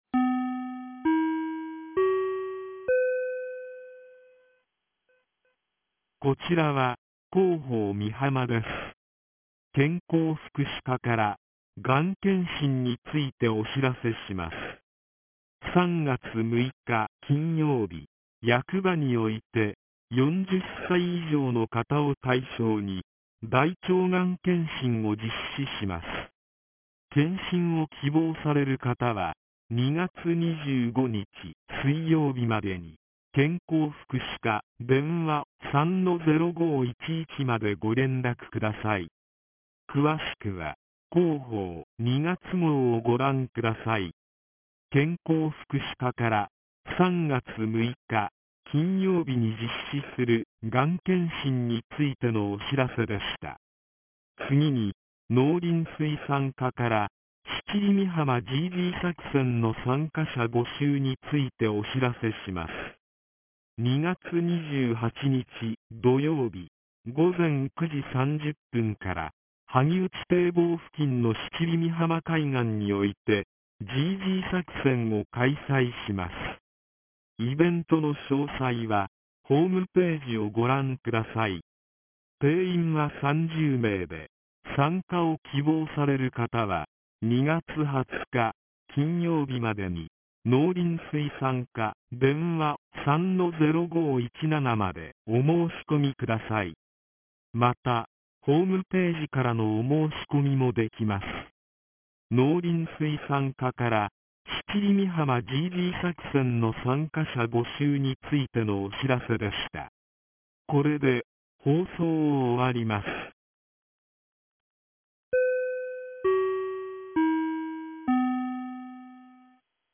■防災行政無線情報■
放送音声